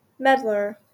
Ääntäminen
GA : IPA : /ˈmɛdlɚ/